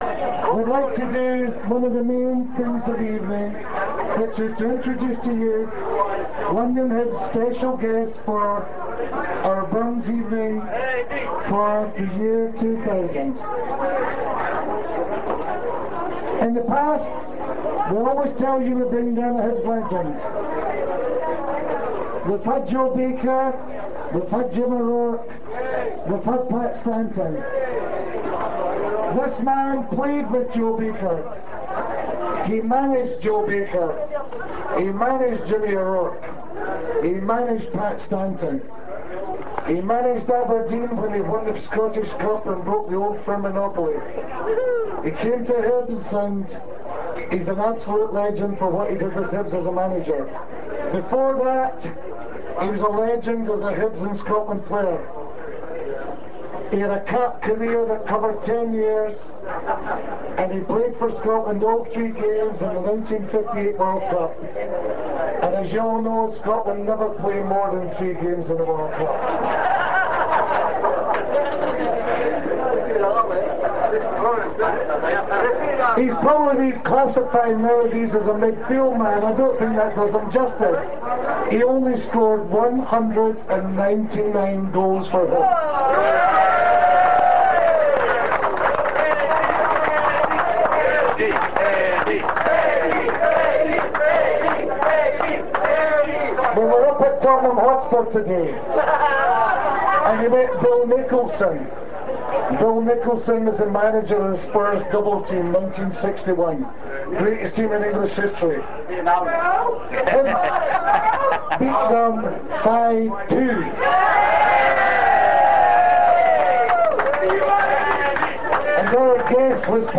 London Hibs Annual Burns Night Supper was held on Saturday 22nd January 2000 at the Kavanagh's Pub, Old Brompton Road.